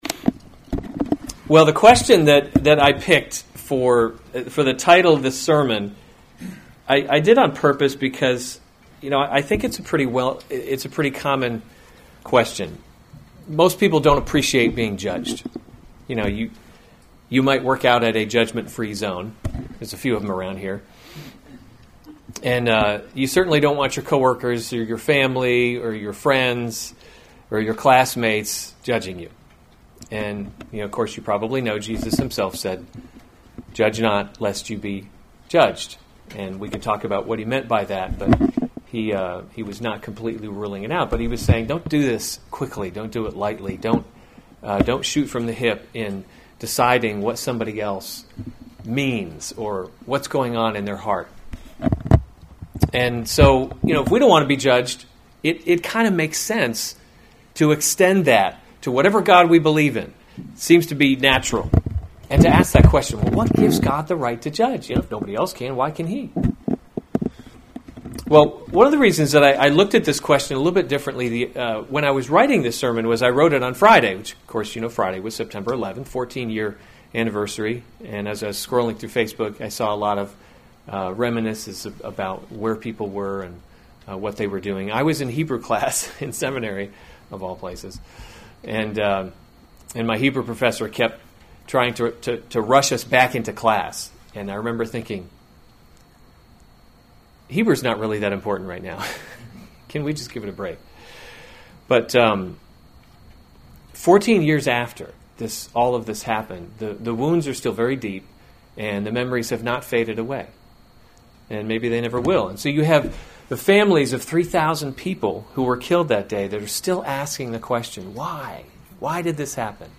September 12, 2015 Amos: He’s Not a Tame Lion series Weekly Sunday Service Save/Download this sermon Amos 1:1-2 Other sermons from Amos 1:1 The words of Amos, who was among the […]